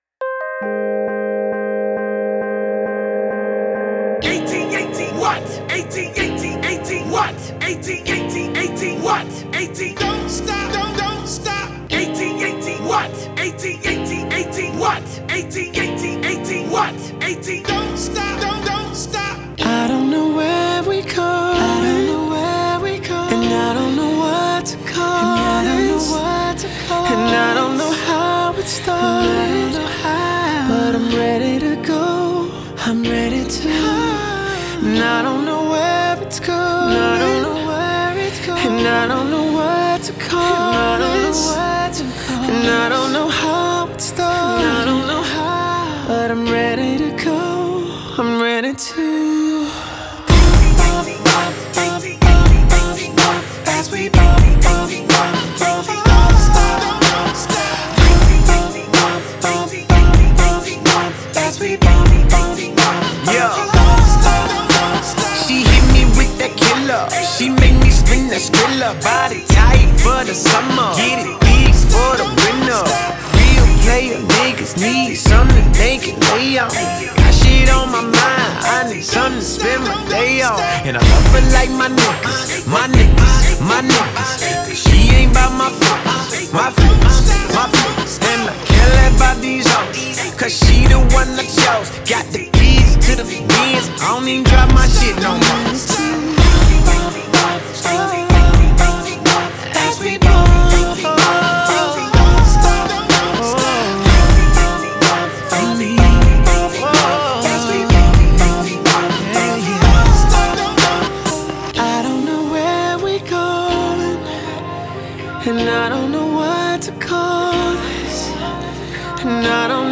The vocals are the highlight of this track.